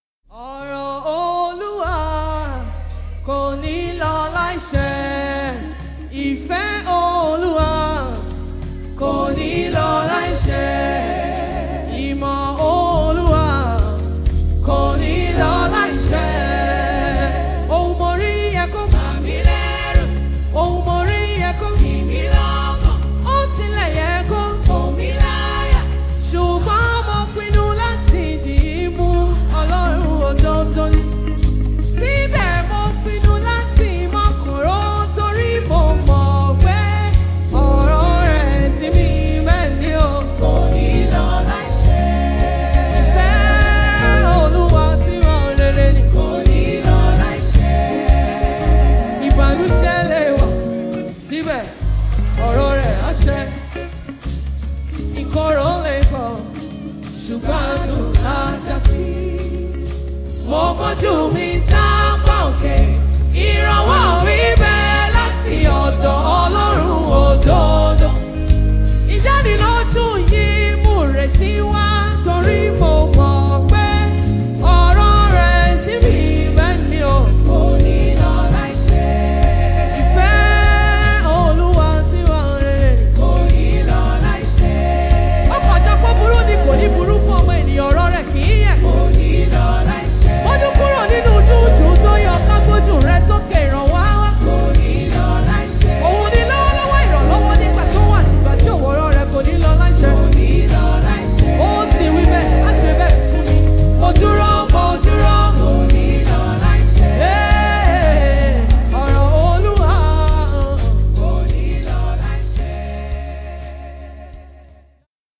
Gospel
high trendy Yoruba Fuji track
Nigerian Yoruba gospel songs
Yoruba Worship Song